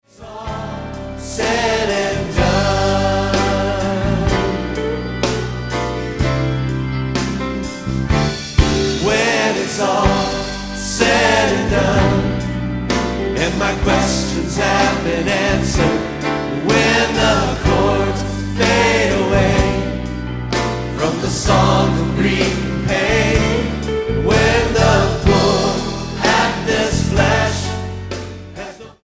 STYLE: MOR / Soft Pop
The musical arrangements are slick, but not too much so.